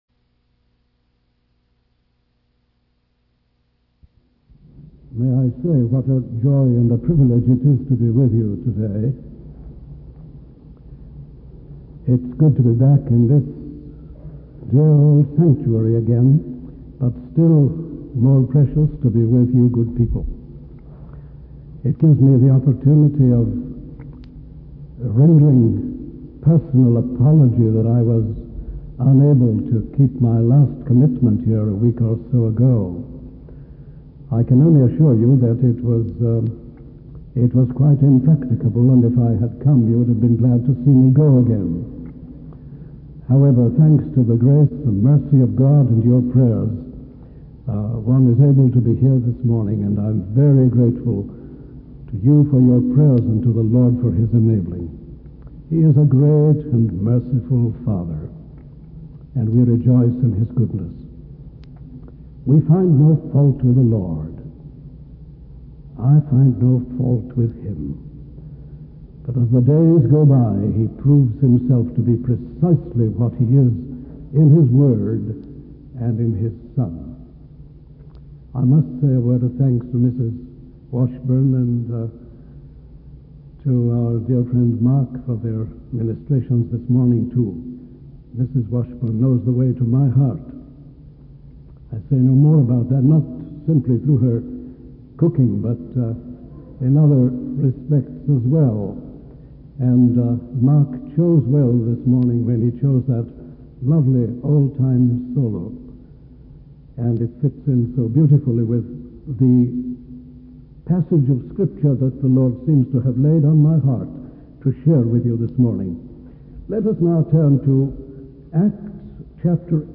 In this sermon, the speaker emphasizes the importance of seeing beyond the superficial details of a story and recognizing the underlying principles.